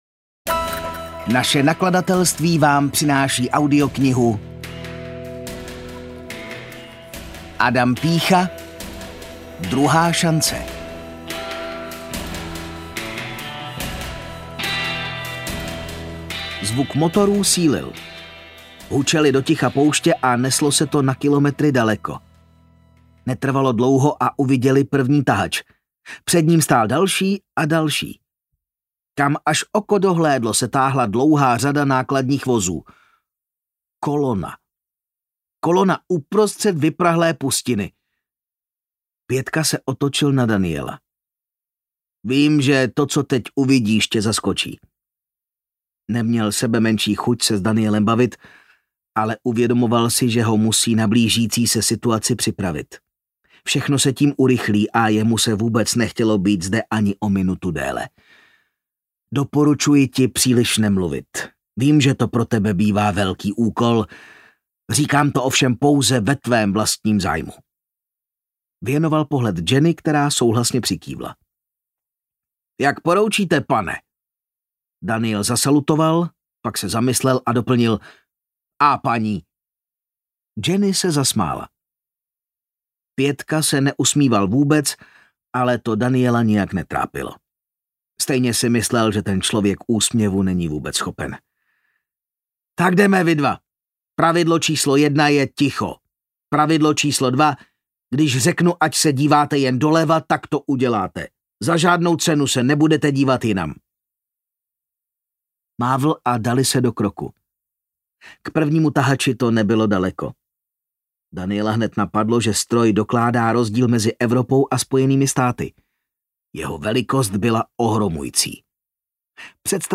Druhá šance audiokniha
Ukázka z knihy